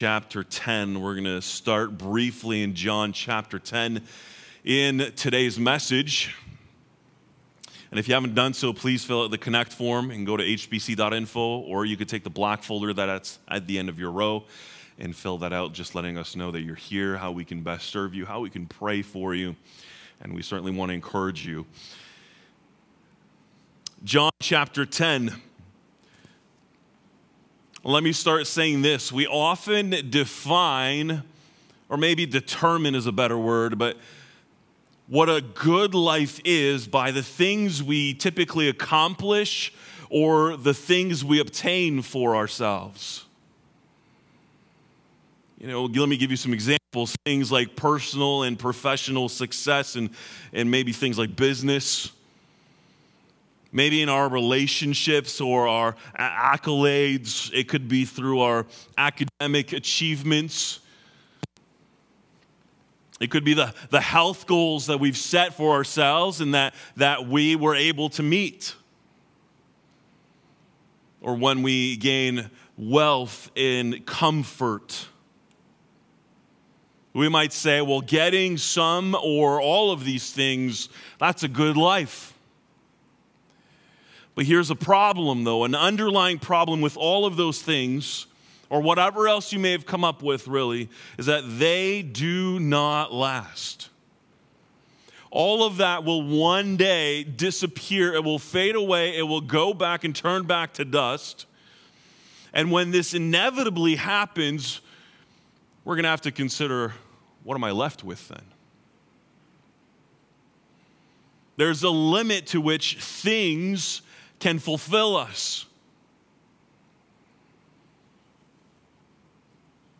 Sermons from Harvest Bible Chapel Podcast - Life | Free Listening on Podbean App